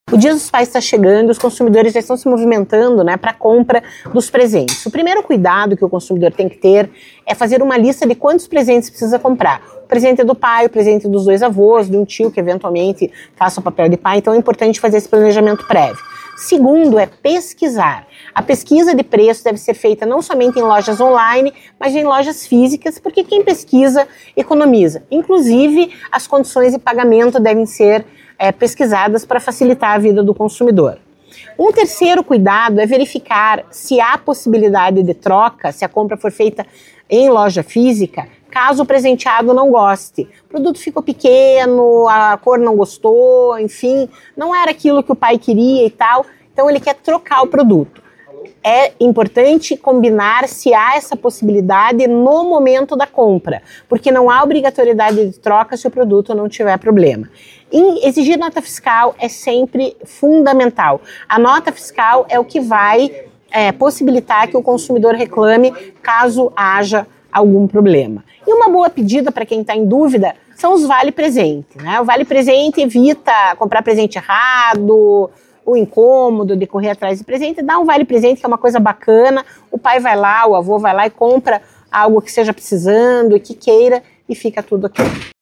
Sonora da coordenadora do Procon-PR, Claudia Silvano, sobre os cuidados necessários durante as compras de Dia dos Pais